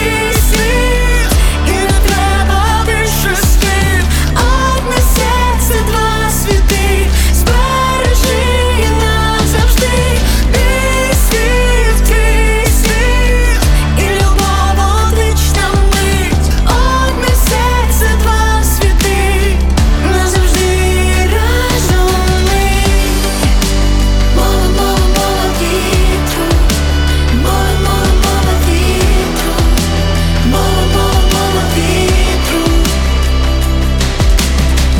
Жанр: Поп / Украинские